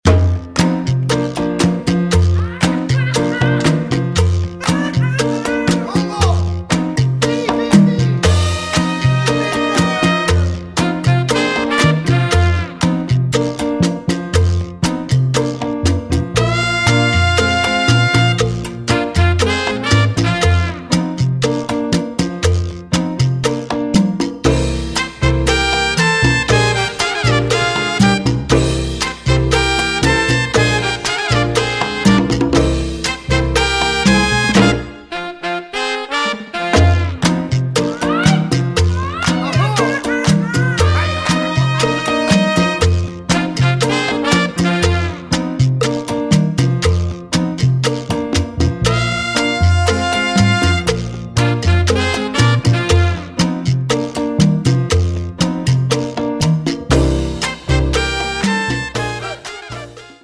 Sample tracks of this Exclusive  Mix CD: